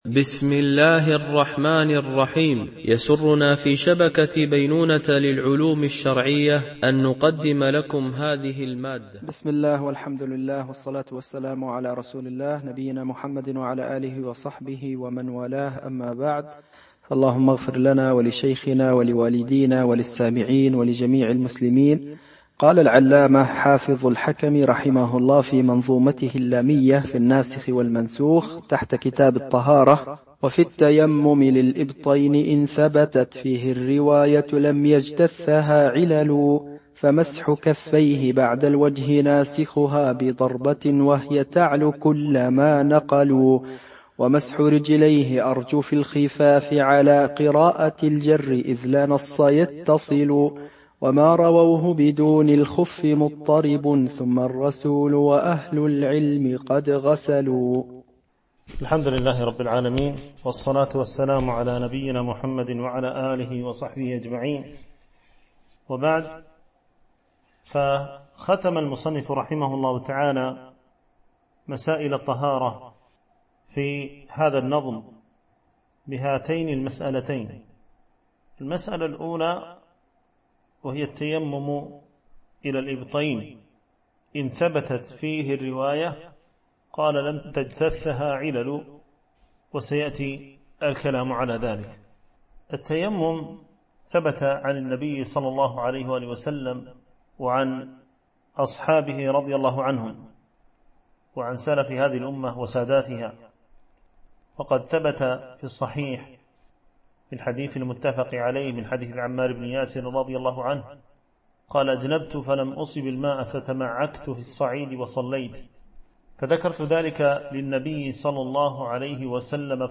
شرح المنظومة اللامية في الناسخ والمنسوخ - الدرس 8